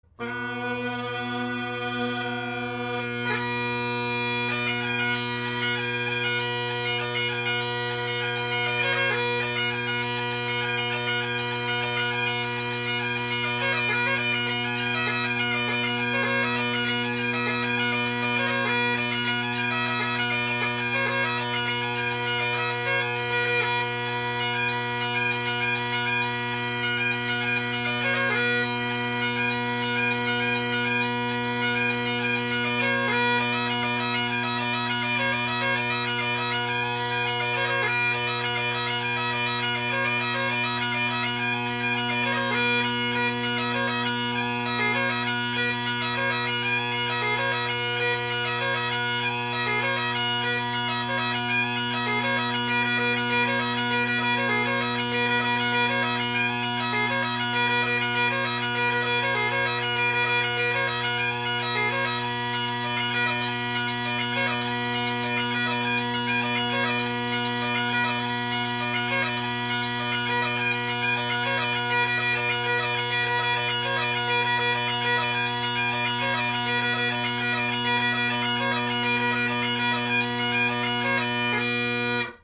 Drone Sounds of the GHB, Great Highland Bagpipe Solo
So the audio that follows is of my band set – the drones + these new drones.
Sorry about the sharp F and flat high A, at times, and occasional cut out.
The mic was placed behind me since we’re listening to the drones here (I’m a drone guy, what can I say?).
The bass drone may be a tad quiet, of course Redwood tenor reeds offer a bold tone without the harshness of other bold tenor reeds, so it may be relative.